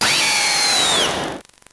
Cri de Zéblitz dans Pokémon Noir et Blanc.